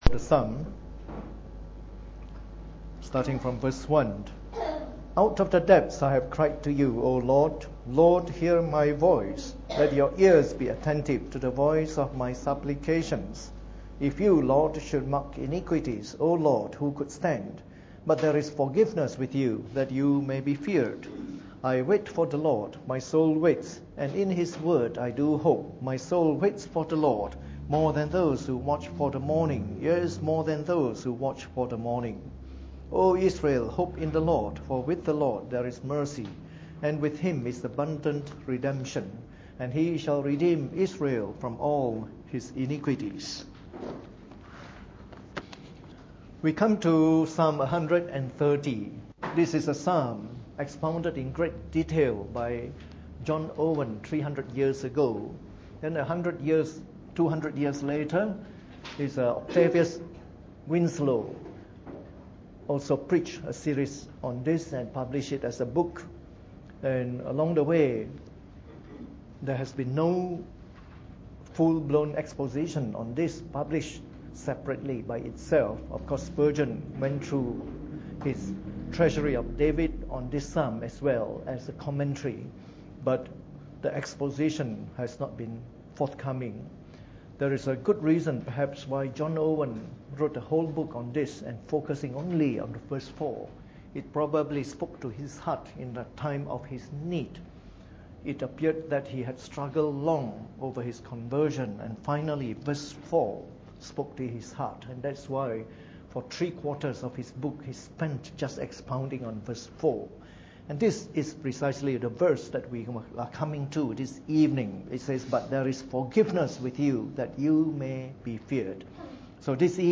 Preached on the 14th of August 2013 during the Bible Study, from our series of talks on Psalm 130.